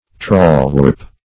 Search Result for " trawlwarp" : The Collaborative International Dictionary of English v.0.48: Trawlwarp \Trawl"warp`\, n. A rope passing through a block, used in managing or dragging a trawlnet.
trawlwarp.mp3